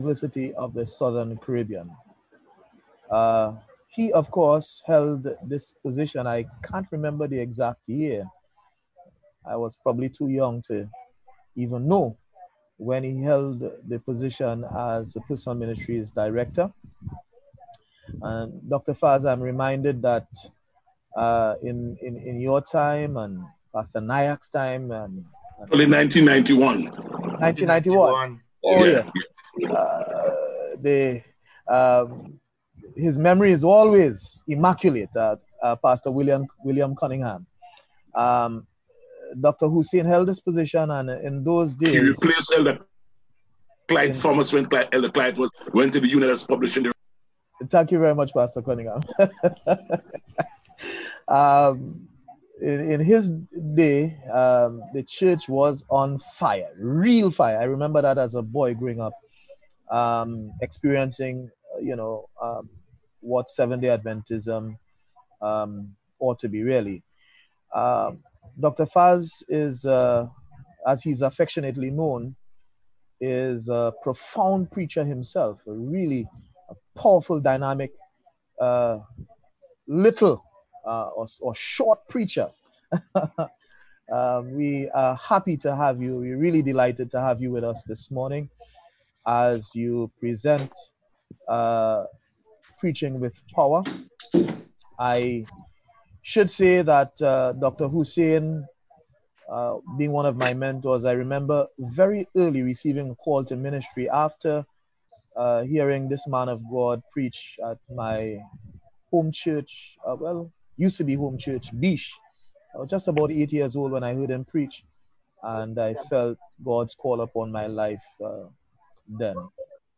Personal Ministries Workshop